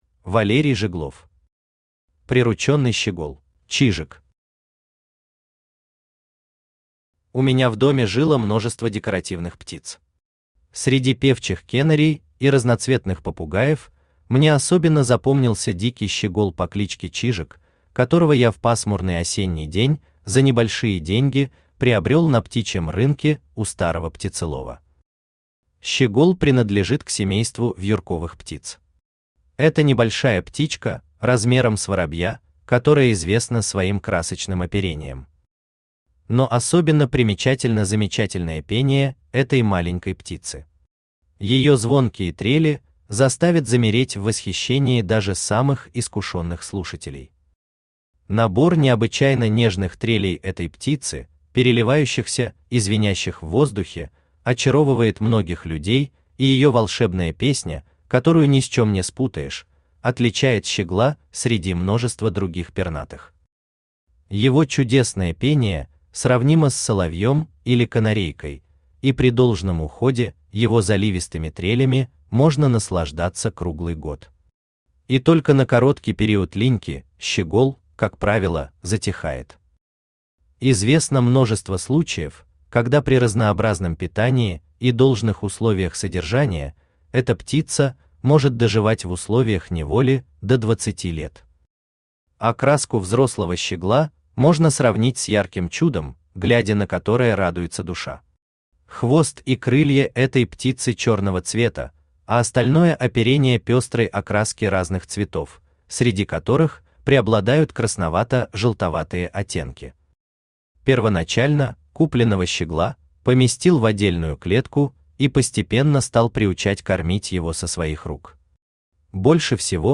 Аудиокнига Прирученный щегол | Библиотека аудиокниг
Aудиокнига Прирученный щегол Автор Валерий Жиглов Читает аудиокнигу Авточтец ЛитРес.